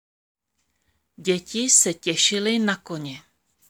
Tady si můžete poslechnout audio na výslovnost DĚ, TĚ, NĚ.